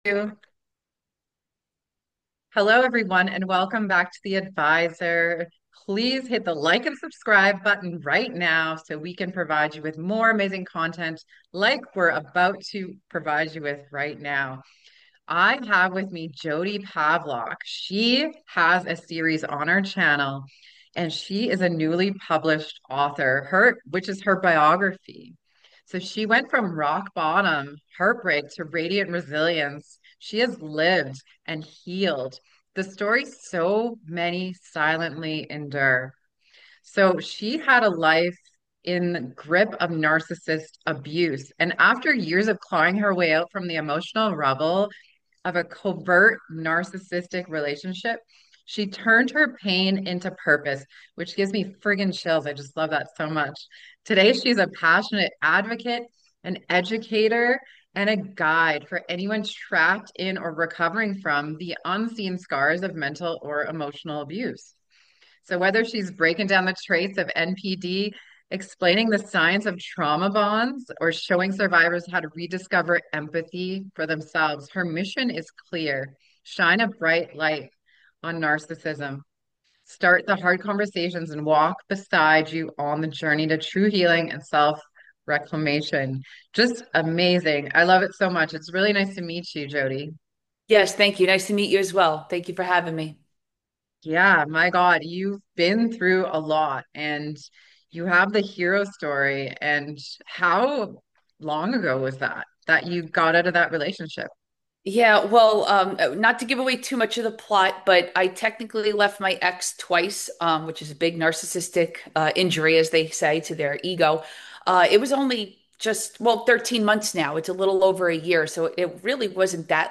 this candid conversation